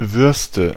Ääntäminen
Ääntäminen Tuntematon aksentti: IPA: /ˈvʏʁstə/ Haettu sana löytyi näillä lähdekielillä: saksa Käännöksiä ei löytynyt valitulle kohdekielelle. Würste on sanan Wurst monikko.